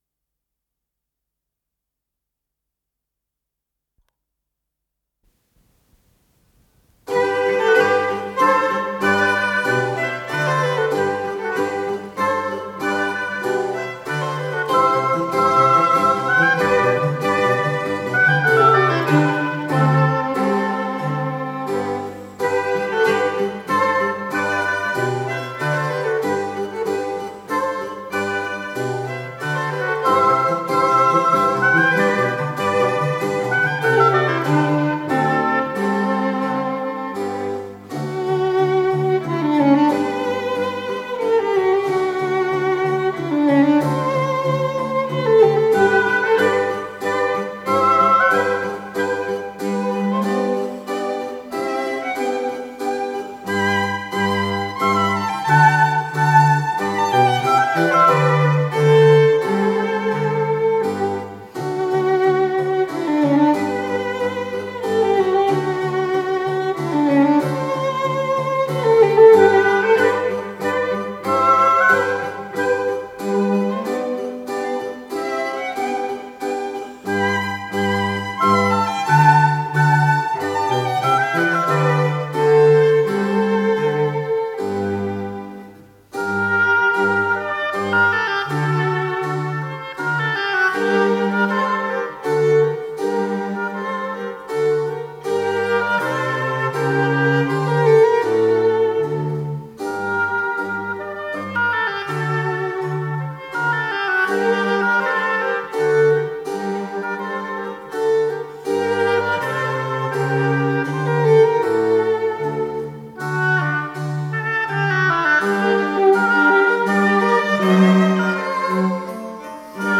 с профессиональной магнитной ленты
соль мажор
ВариантДубль моно